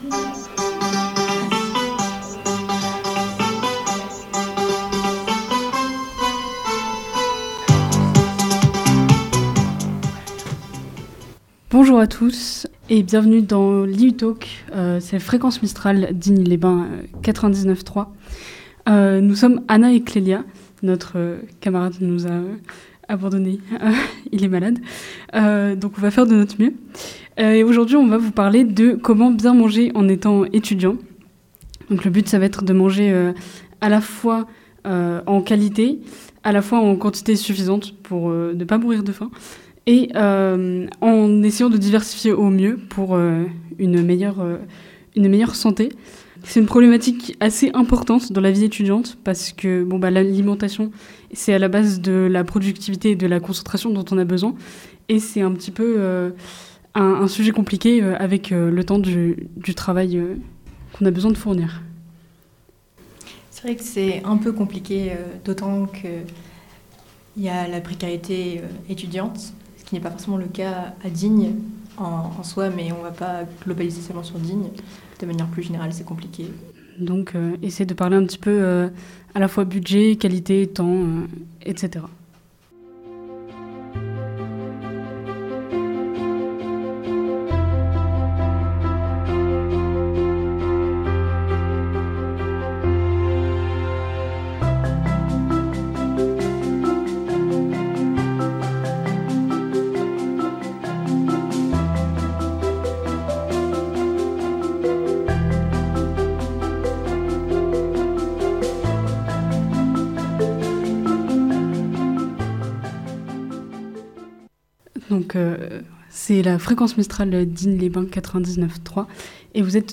Une émission réalisée entièrement par des étudiantes, et étudiants de l'IUT d'Aix-Marseille Site de Digne-les-Bains saison 2023-2024, en 2ème année d'agronomie.